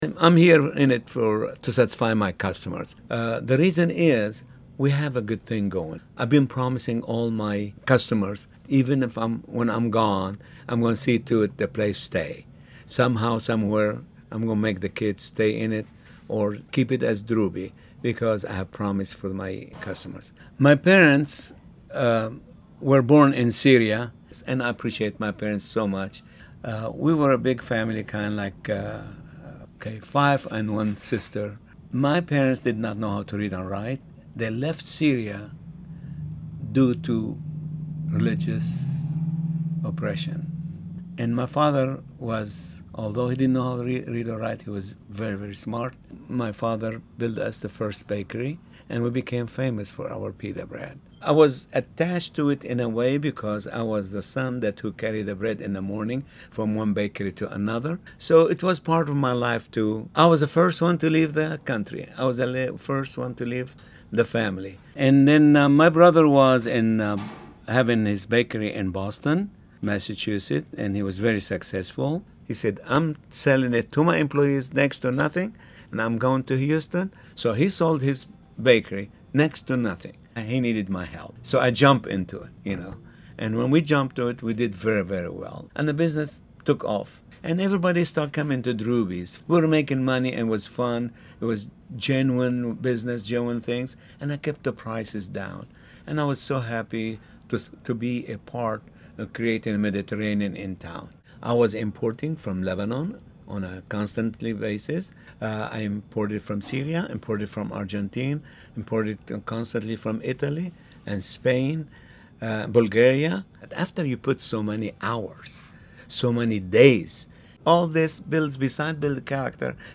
interview excerpt